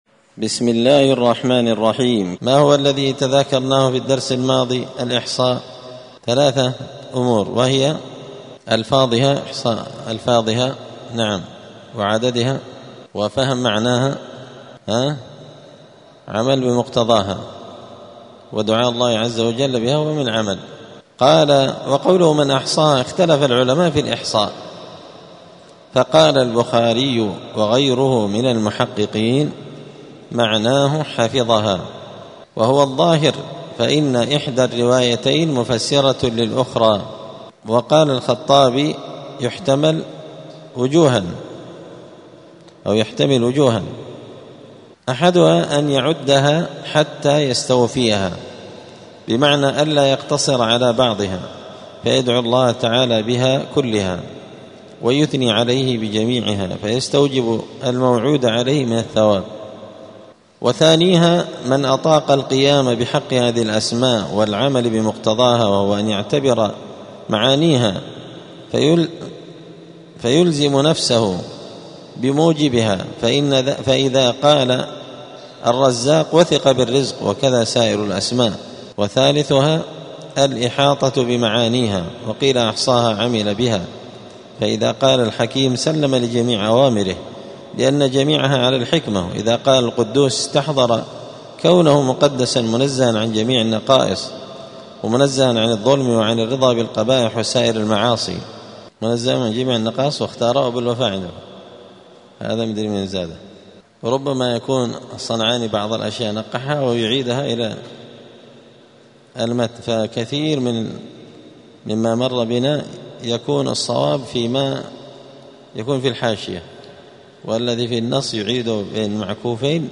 *الدرس الحادي عشر (11) {اﻟﻴﻤﻴﻦ اللغو}*